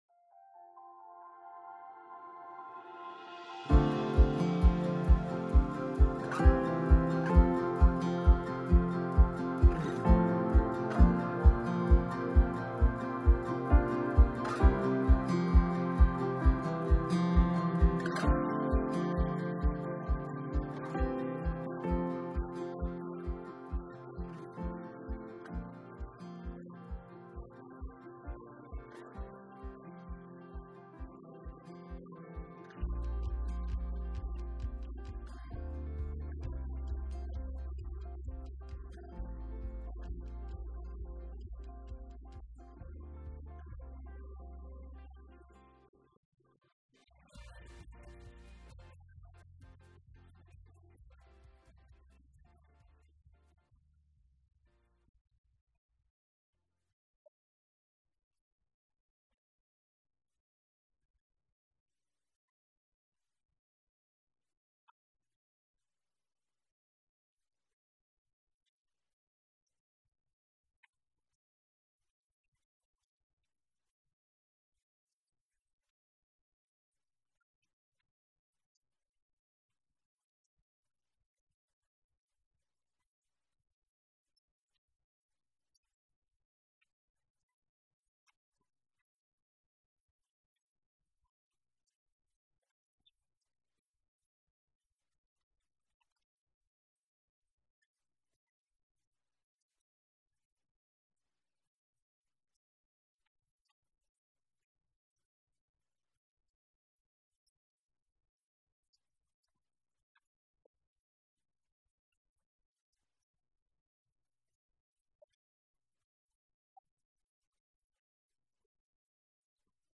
The Commissioned Life Wrapped in a Jar of Clay Part 3 in the series, Building His Church: The Commission Wall Download the sermon notes (adult version)